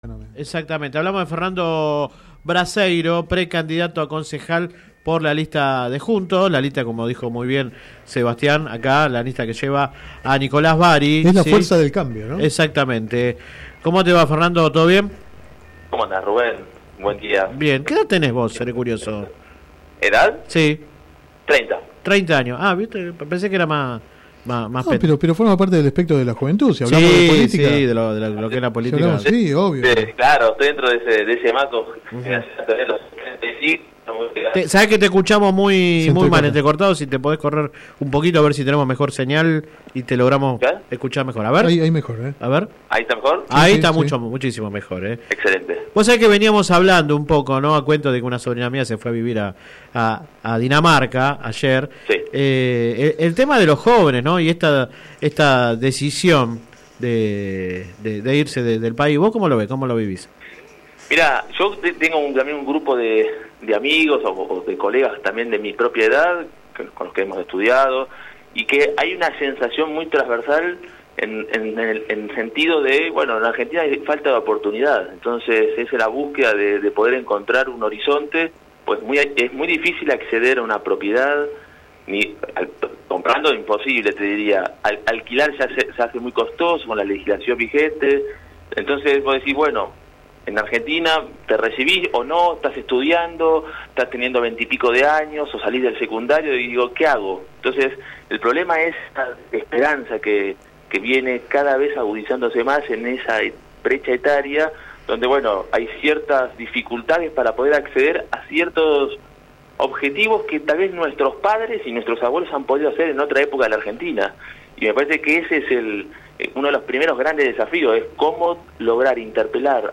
Ambos dirigentes del radicalismo de Lomas de Zamora hablaron en el programa radial Sin Retorno (lunes a viernes de 10 a 13 por GPS El Camino FM 90 .7 y AM 1260).
Click acá entrevista radial